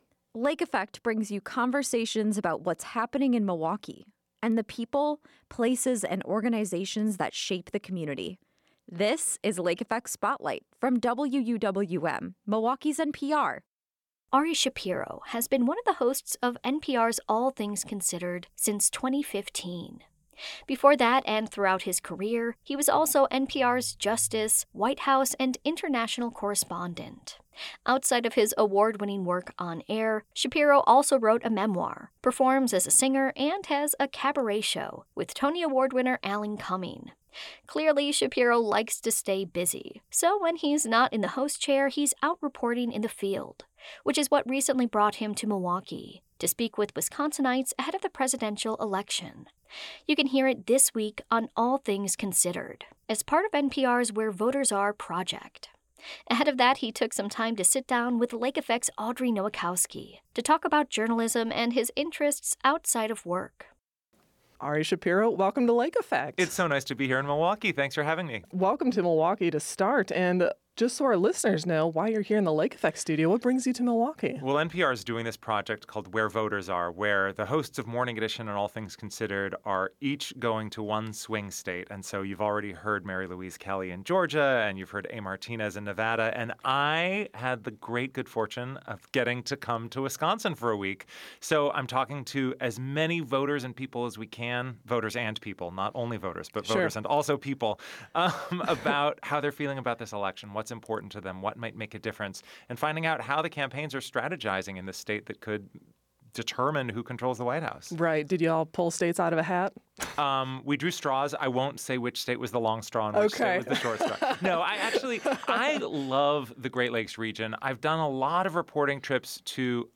From back seat listener to NPR host: A conversation with Ari Shapiro